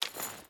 Water Chain Run 2.wav